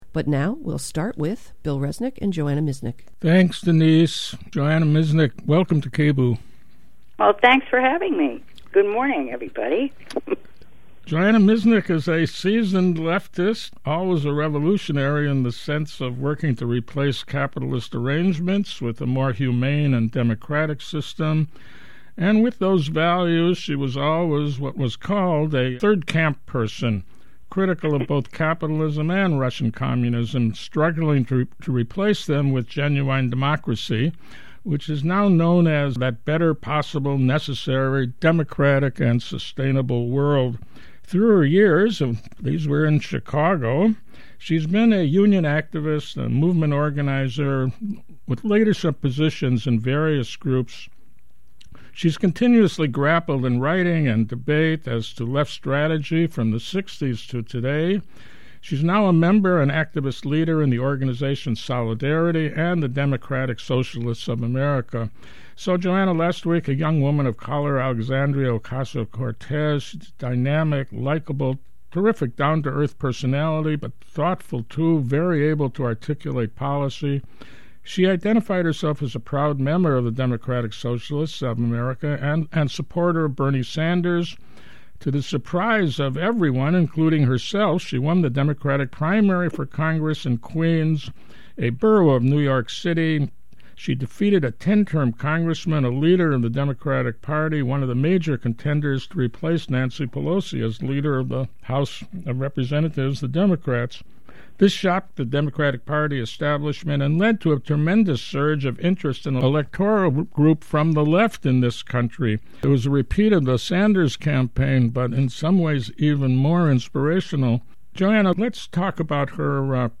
CONVERSATION ABOUT DSA ELECTORAL SUCCESSES AND THE FUTURE